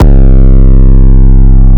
REDD 808 (21).wav